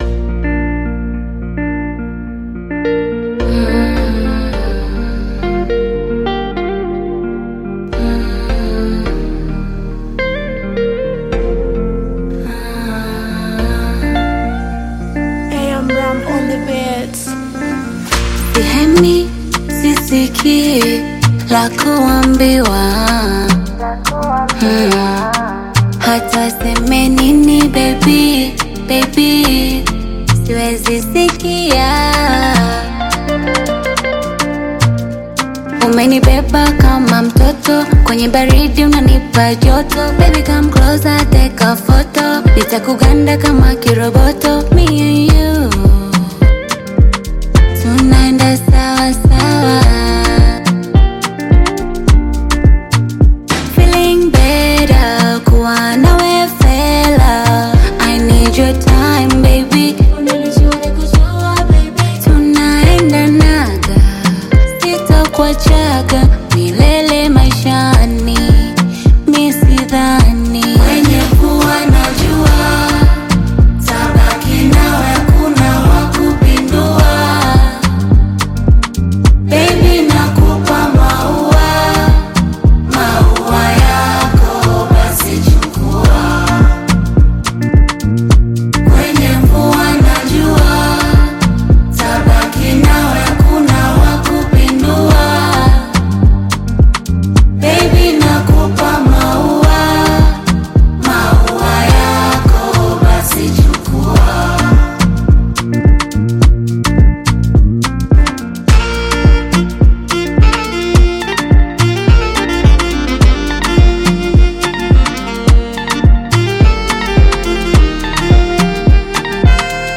Tanzanian Bongo Flava artist, singer, and songwriter
Bongo Flava